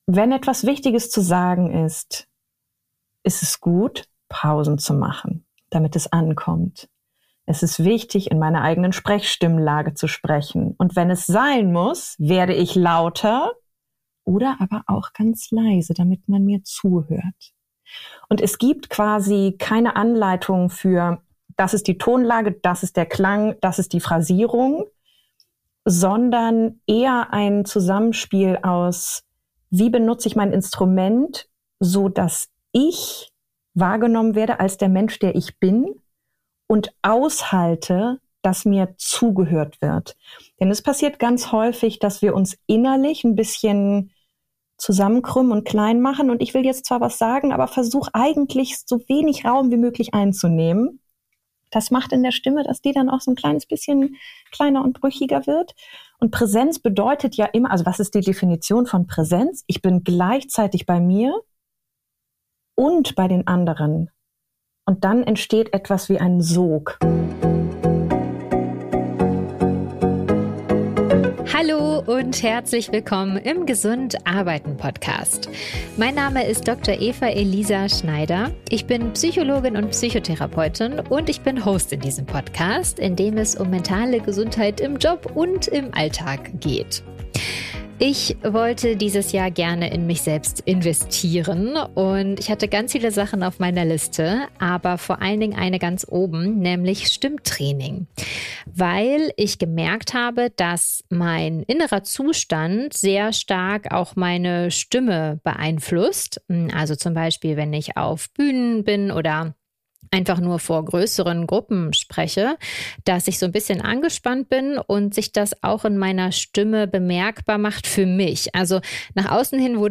Stimmtraining